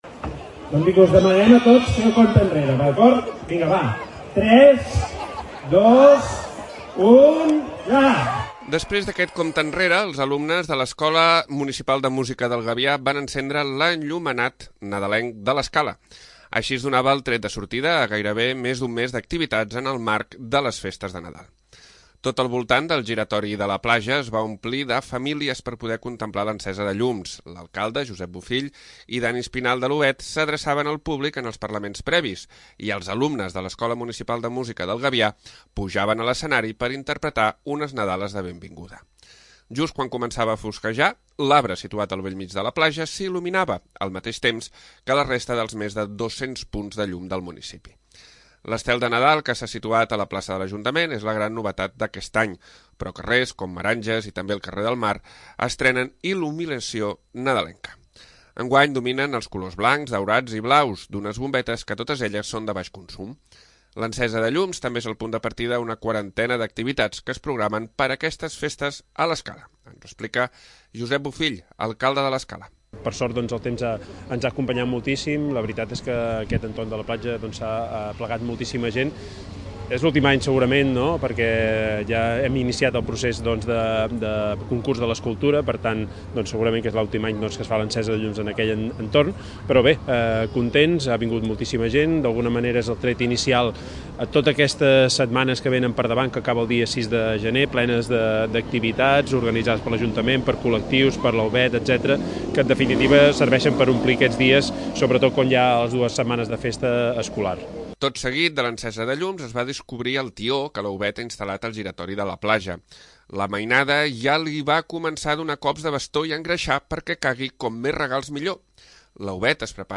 2. L'Informatiu
Després d'aquest compte enrere, els alumnes de l'Escola de Música del Gavià van encendre l'enllumenat de Nadal de l'Escala.